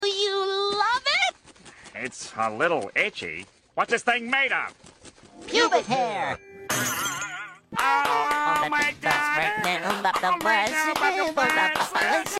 Follow for more deep fried FreakBob sound effects free download